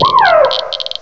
cry_not_tynamo.aif